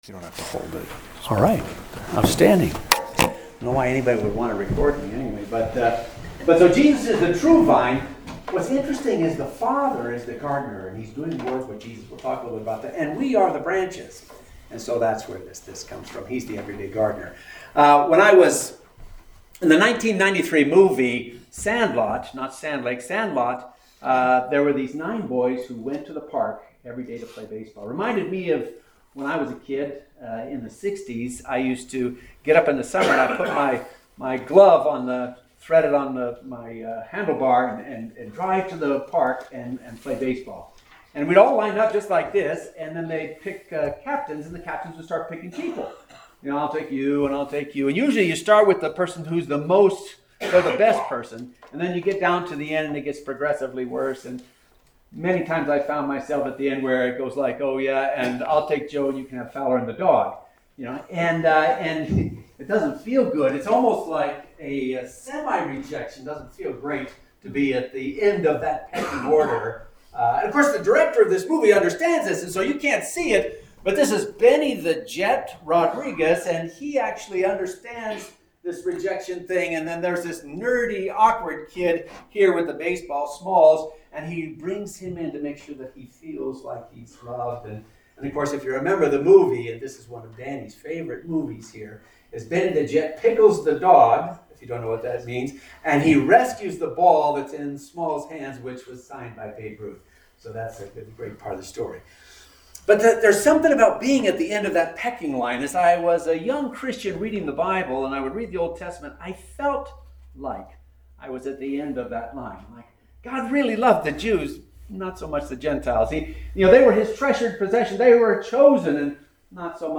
Sermons | Sand Lake Chapel